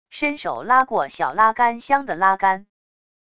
伸手拉过小拉杆箱的拉杆wav音频